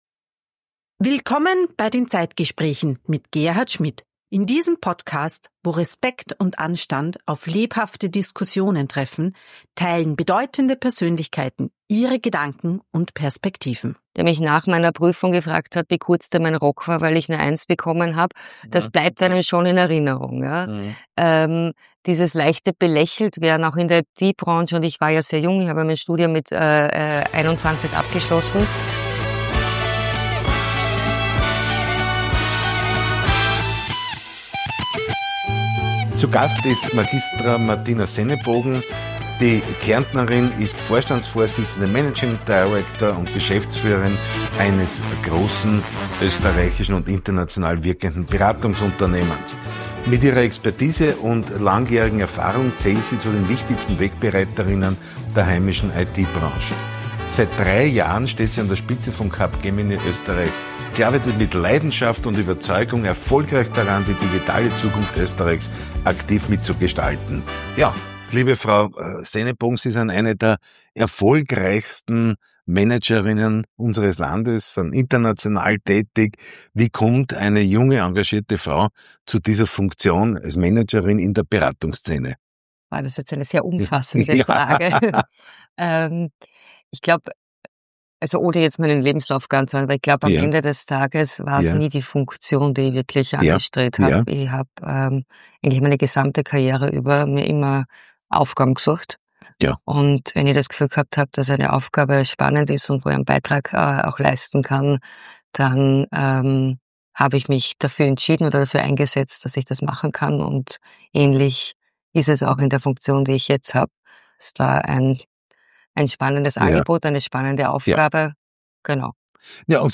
Beschreibung vor 4 Monaten Gespräche auf Augenhöhe, auf Höhe der Zeit: Die „ZEITGESPRÄCHE“ sind ein eindrückliches Zeugnis von Anstand und Respekt.